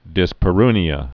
(dĭspə-rnē-ə)